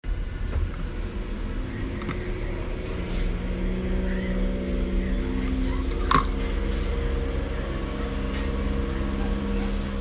8inch electric scooter hub motor#electric